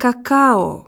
La syllabe accentuée a une prononciation plus longue et plus appuyée que les autres.